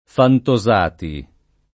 [ fanto @# ti ]